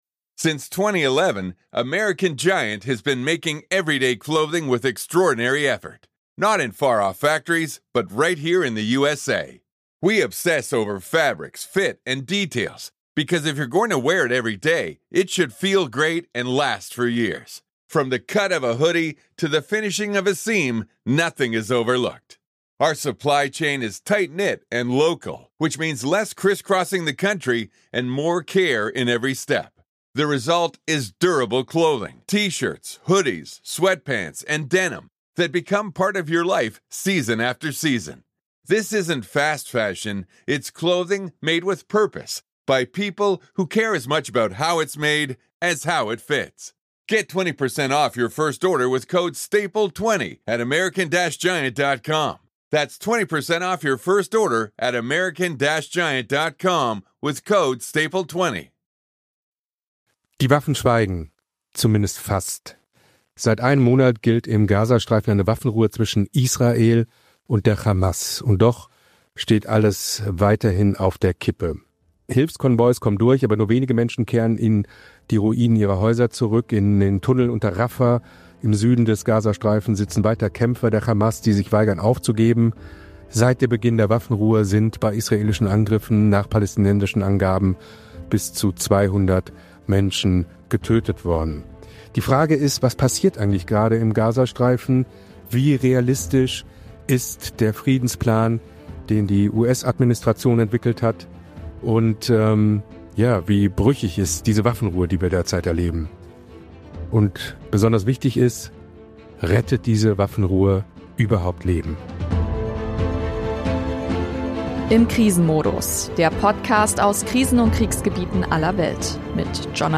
Ein Chirurg aus Gaza und ein Nahost-Experte erklären, warum die Waffenruhe kaum wirkt – und was passieren müsste, damit Menschen dort wirklich überleben können.